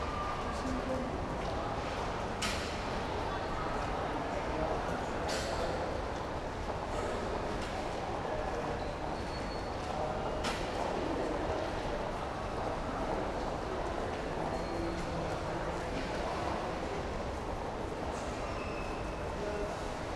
hall_large_ambiX.wav